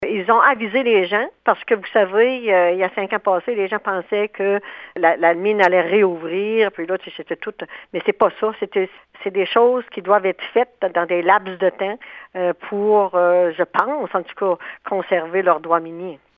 La mairesse de Murdochville, Délisca Ritchie-Roussy, explique que les travaux devraient se dérouler sur environ 4 à 6 semaines et n’ont rien à voir avec une éventuelle réouverture potentielle de l’ancienne mine fermée en 1999 :